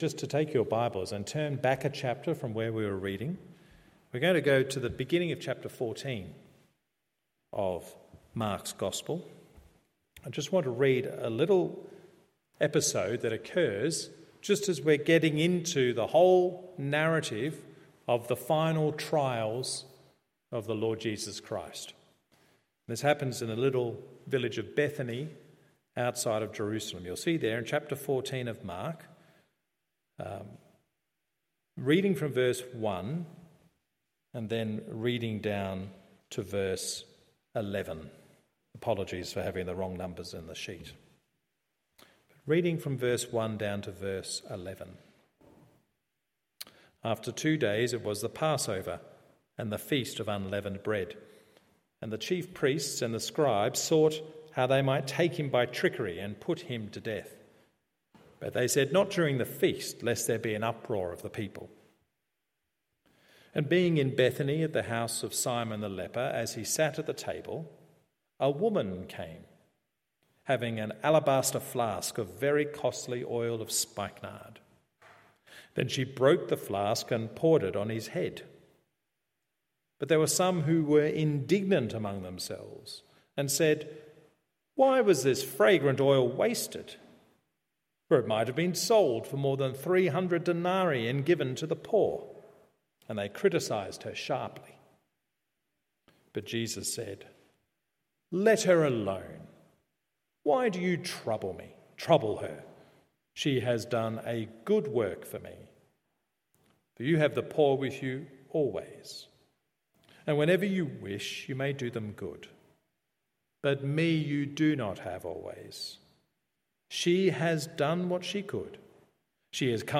Good Friday Service Mark 14:1-11 Mark 15…